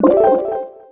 pickup_spawn_01.wav